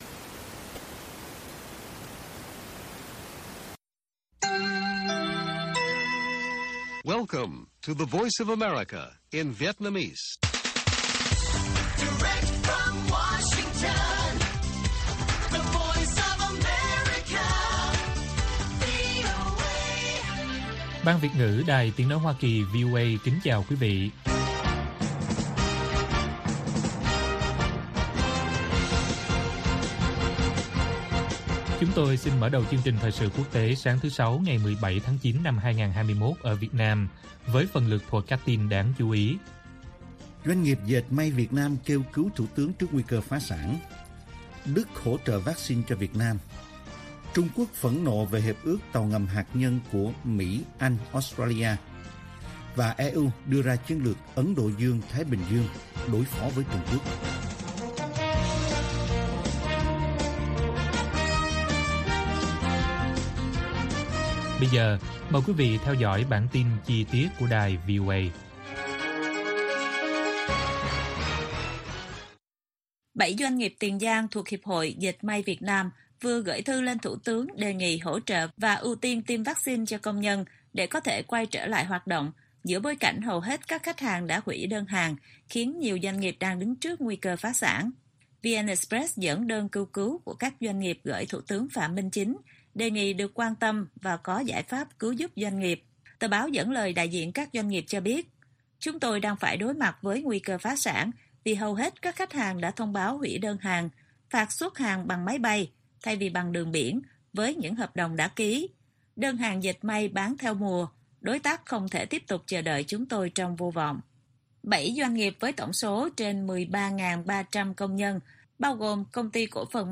Bản tin VOA ngày 17/9/2021